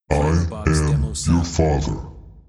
“I am your father” Clamor Sound Effect
Can also be used as a car sound and works as a Tesla LockChime sound for the Boombox.